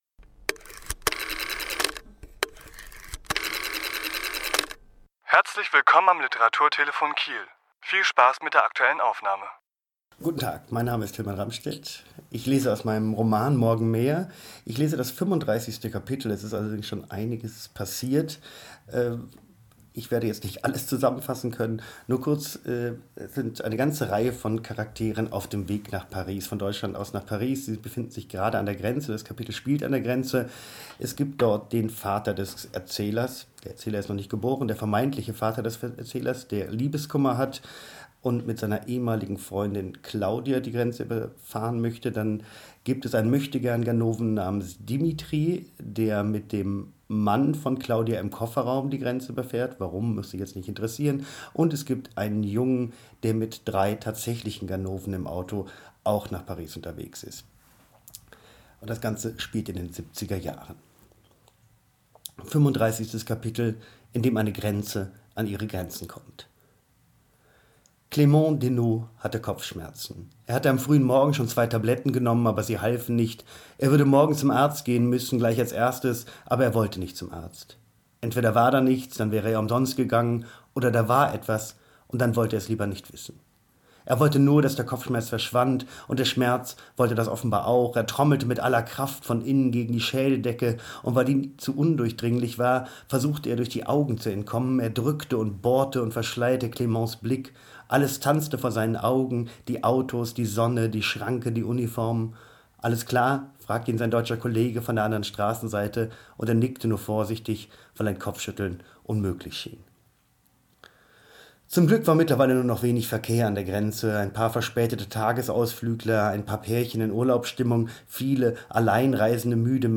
Autor*innen lesen aus ihren Werken
Die Aufnahme entstand bei einer Lesung im Literaturhaus Schleswig-Holstein am 12.1.2017.